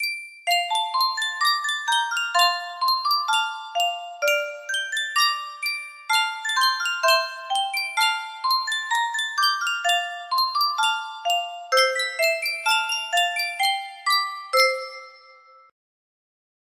Yunsheng Music Box - Jeanie With the Light Brown Hair 5998 music box melody
Full range 60